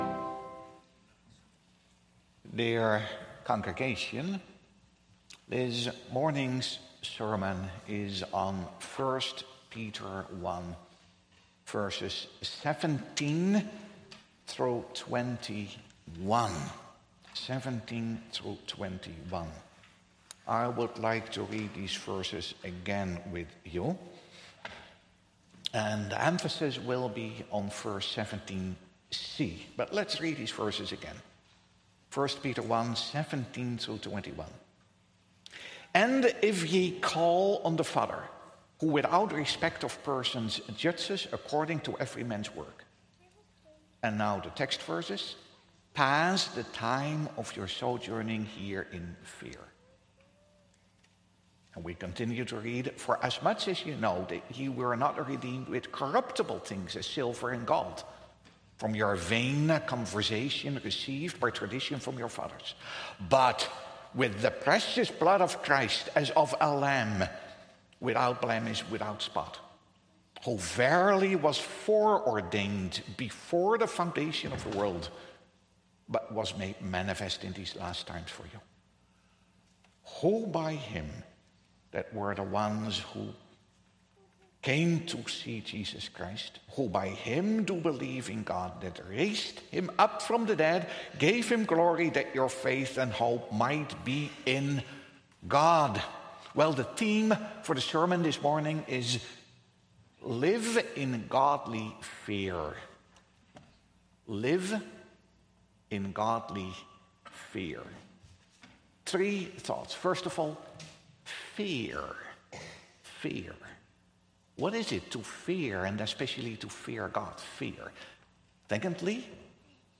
Sermon Audio | Providence Reformed Church